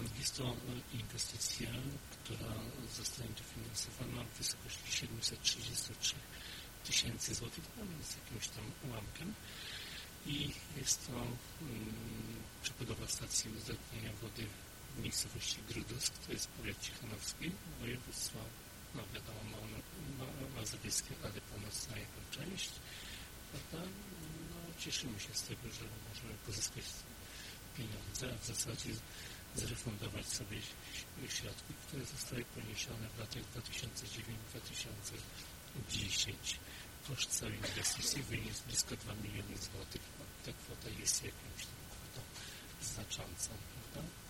Wypowiedź wójta gminy Grudusk, Jacka Oglęckiego